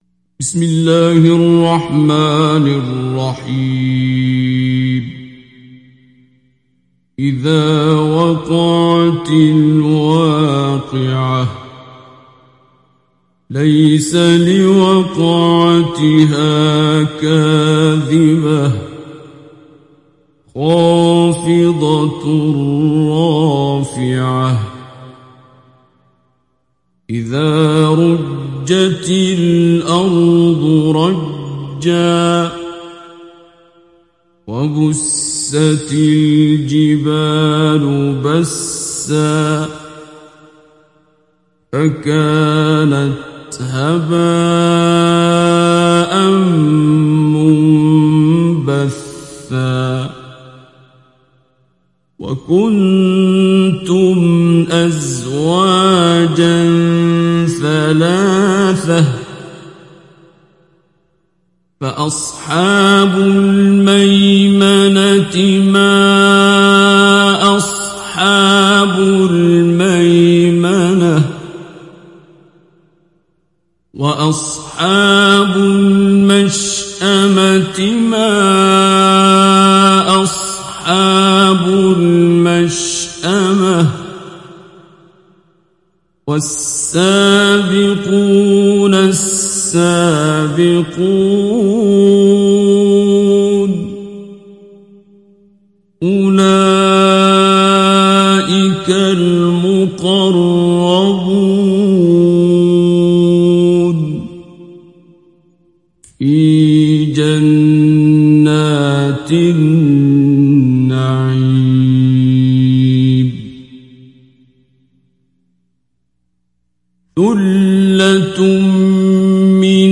Surat Al Waqiah Download mp3 Abdul Basit Abd Alsamad Mujawwad Riwayat Hafs dari Asim, Download Quran dan mendengarkan mp3 tautan langsung penuh
Download Surat Al Waqiah Abdul Basit Abd Alsamad Mujawwad